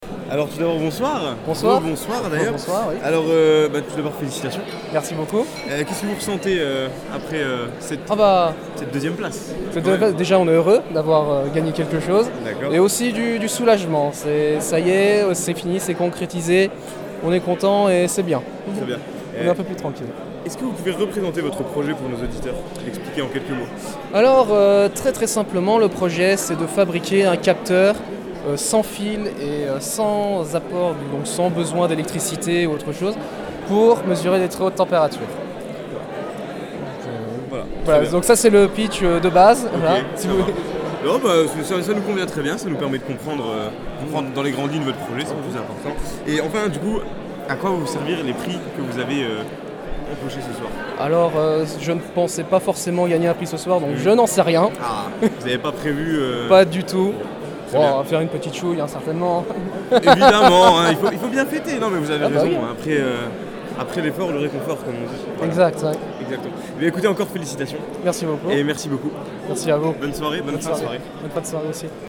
Cette année, Radio Campus Lorraine a eu l’honneur d’animer la 6ème édition du trophée MC6.
interview-2eme.mp3